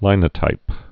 (līnə-tīp)